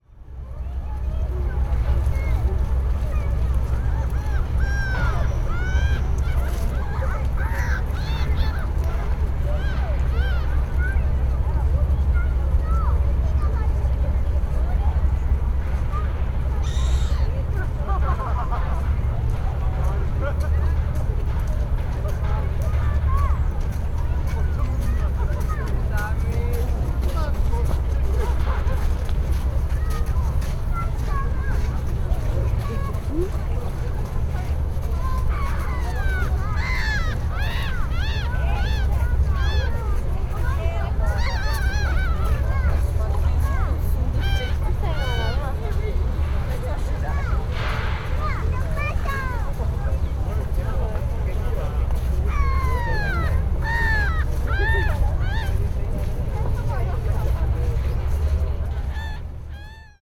Jardin des Tuileries, Paris, 12/11/2023
Alors on a retraversé le jardin sous la pluie — je m’étais arrêté trois minutes au bord du bassin pour prendre un son, et le photographier la grande roue au fond.